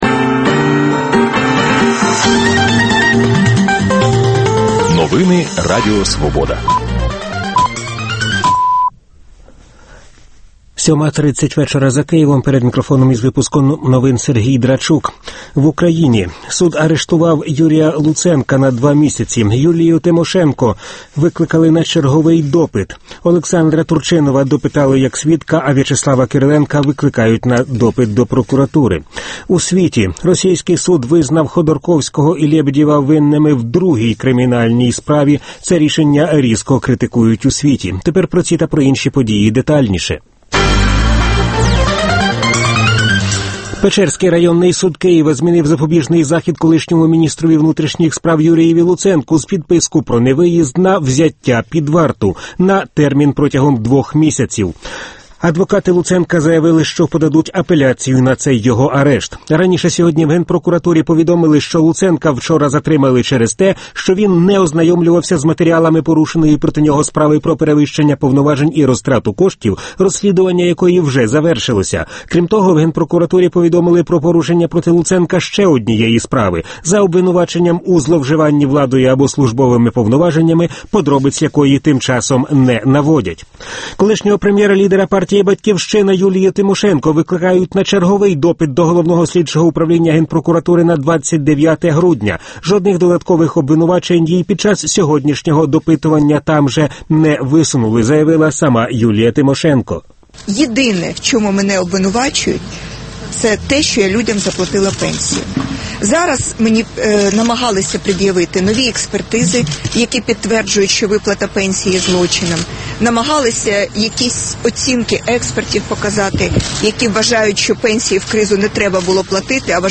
Дискусія про головну подію дня.